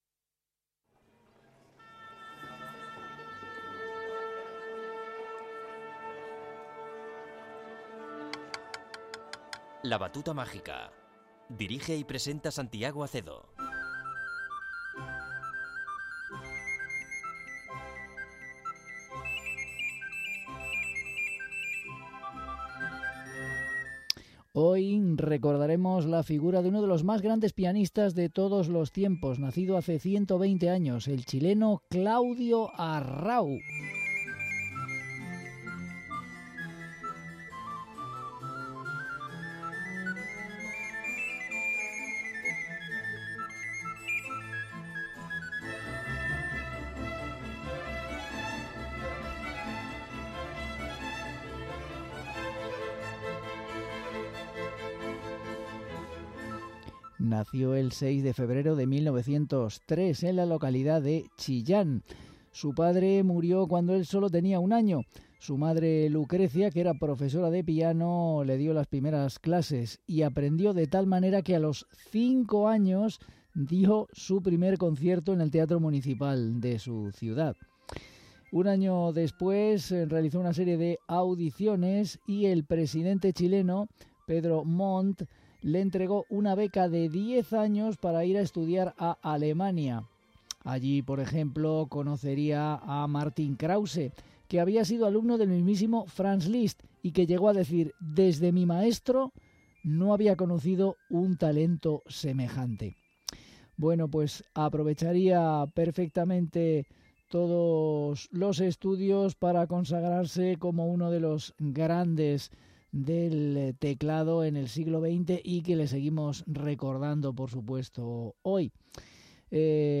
Conmemoramos el 120 aniversario del pianista CLAUDIO ARRAU